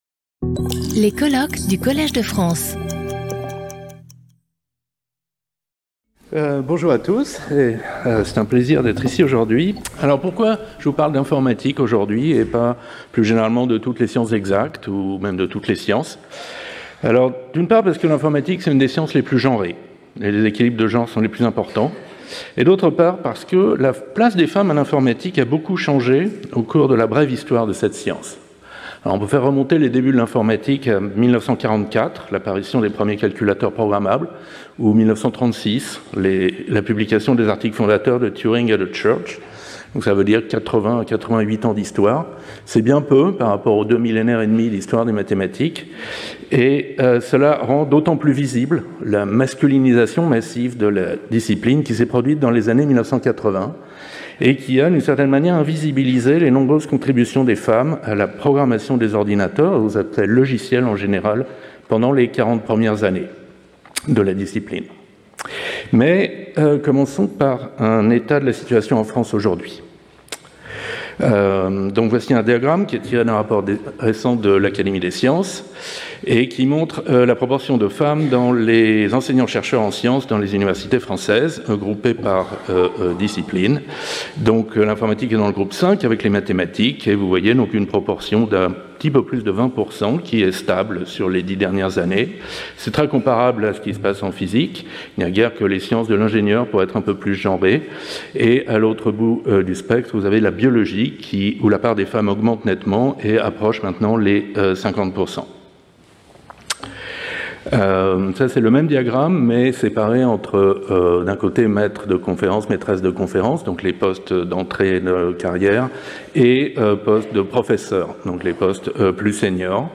Skip youtube video player Listen to audio Download audio Audio recording Session moderated by Françoise Combes. Each 30 minute presentation is followed by 10 minutes of discussion. Abstract The proportion of women in computing, both in industry and academia, is one of the lowest among scientific disciplines.